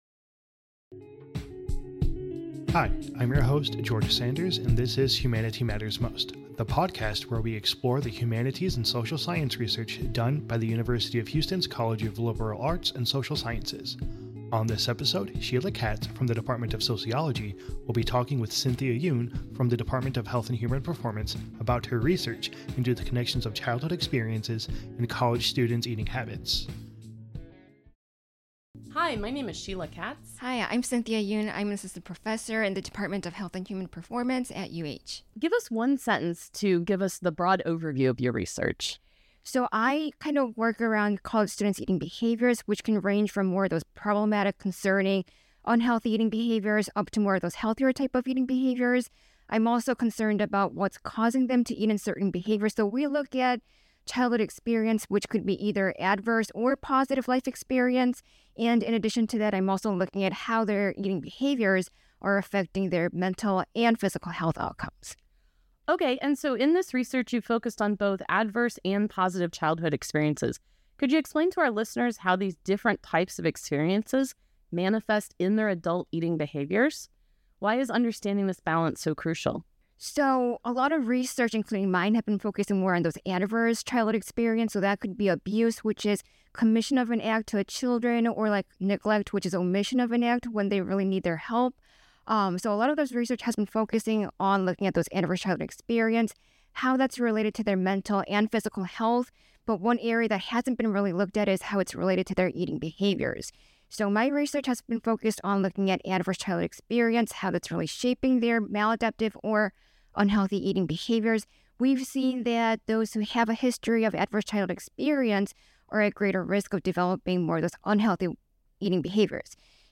Tune in for conversations with powerful minds from the University of Houston College of Liberal Arts and Social Sciences.